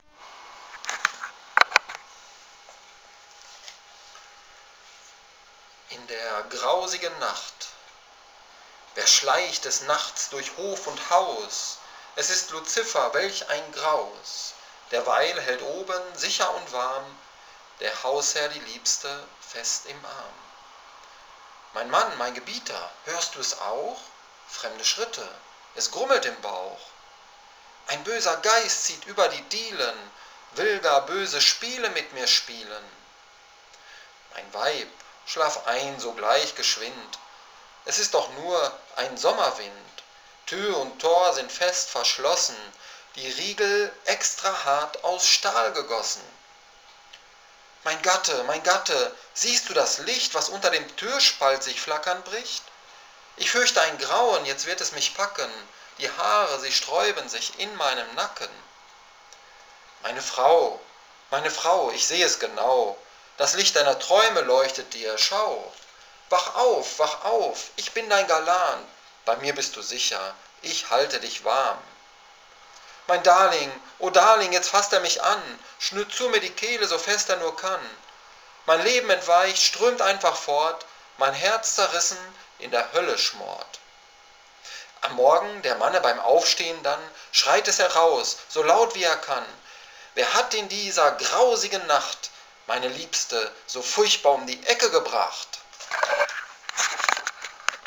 Ich dachte, liest Deinen treuen Homepagegästen mal was vor.